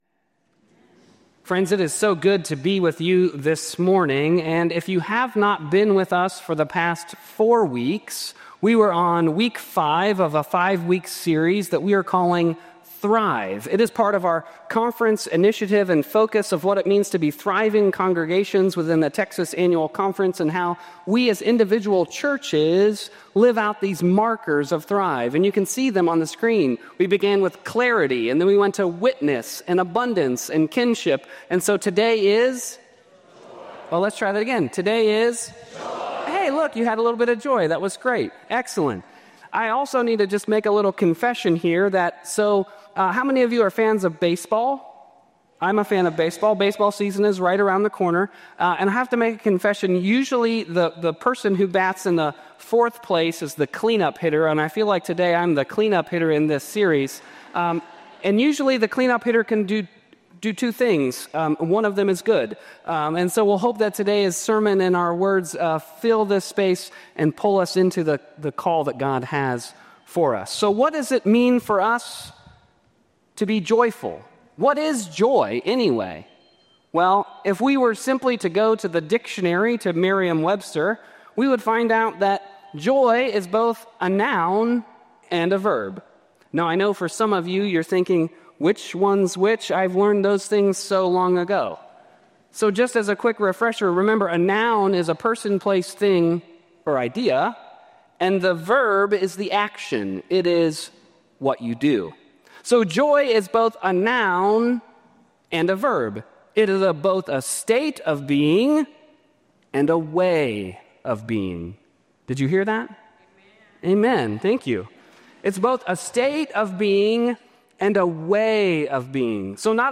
Service Type: Traditional